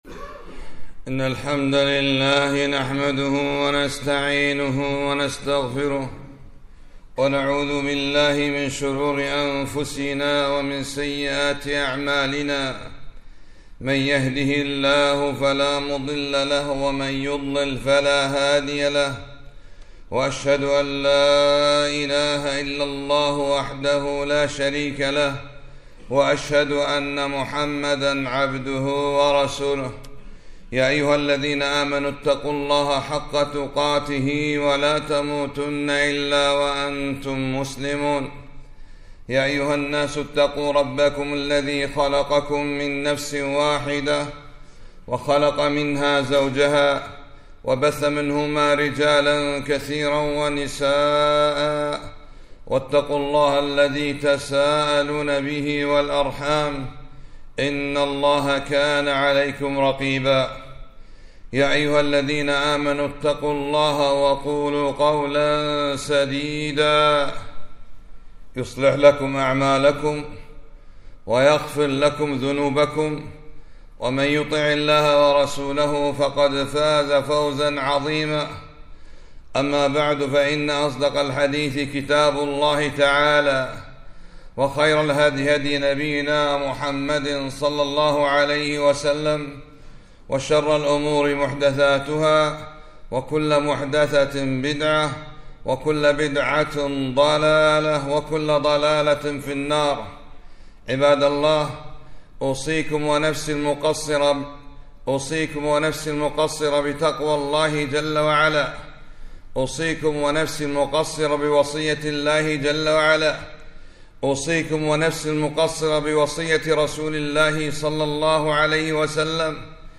خطبة - (إن الله يحب التوابين)